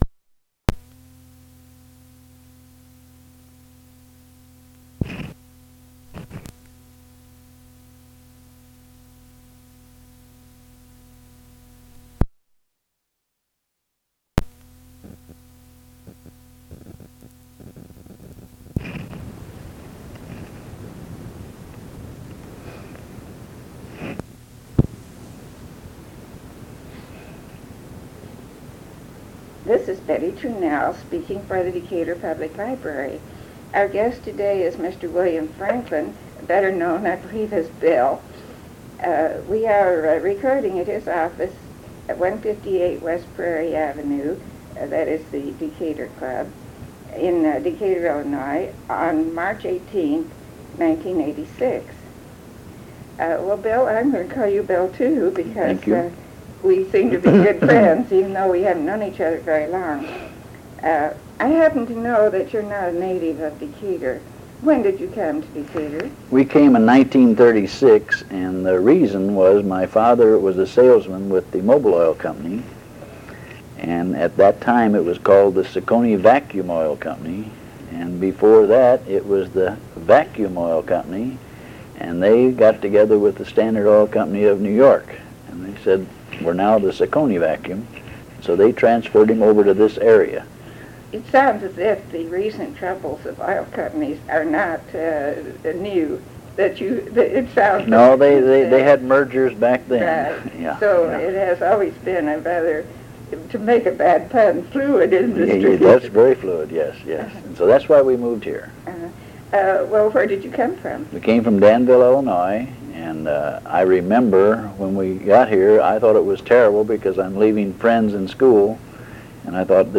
interview
oral history